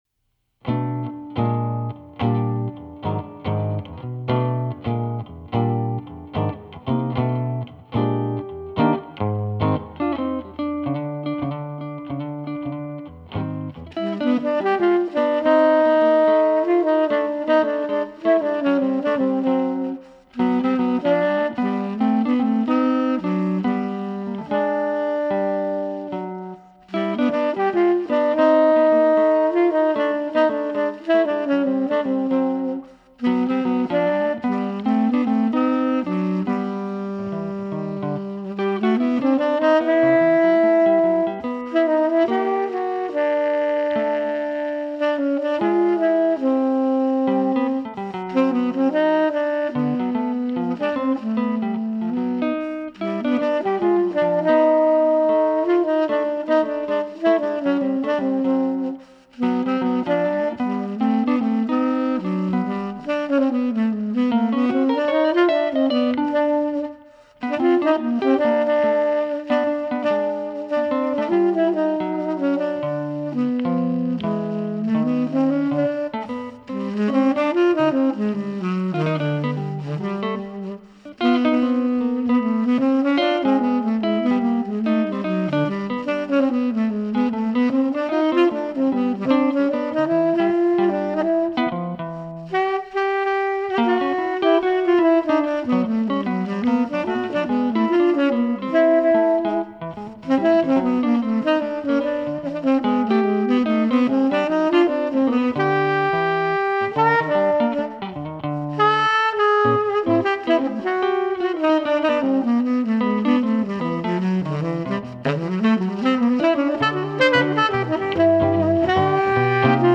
играет дуэтом с тенор саксофонистом